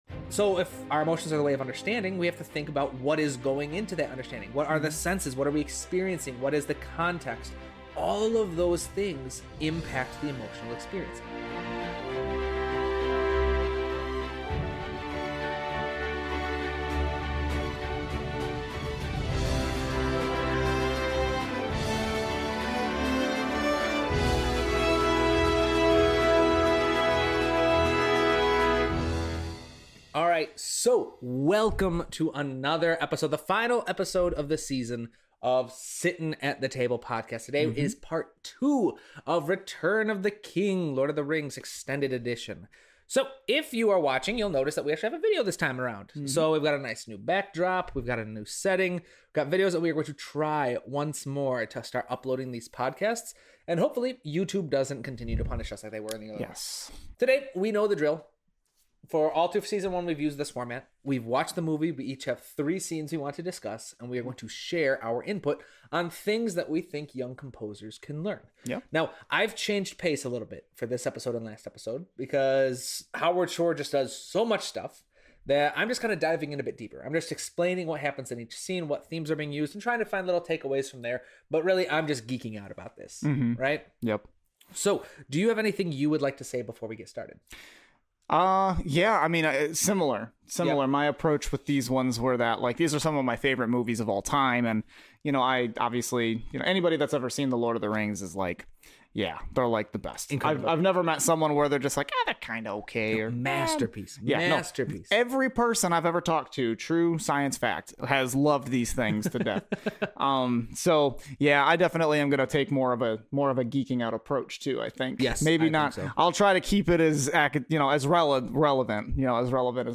In this episode, a therapist and professional film composer each react to their favorite scenes from Princess Mononoke and discuss what young film composers can learn from studying these scenes.